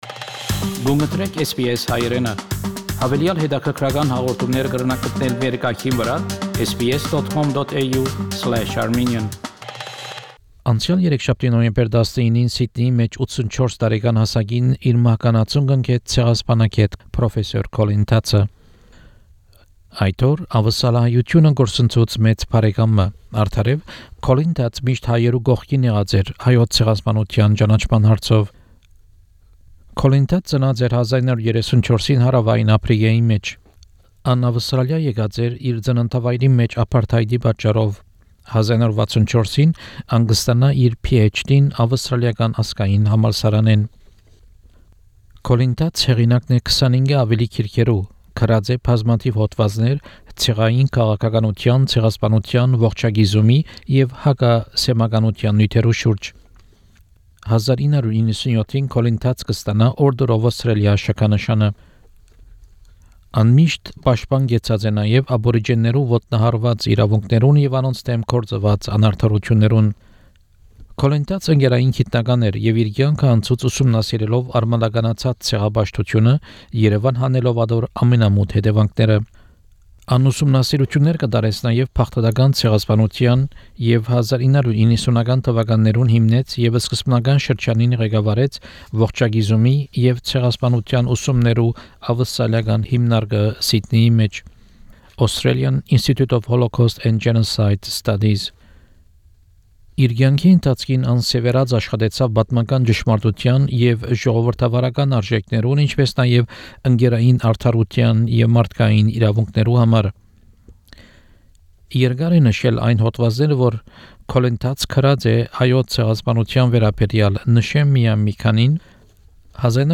This segment includes an introduction in Armenian and the last interview (in English) Prof. Tatz gave to SBS Armenian.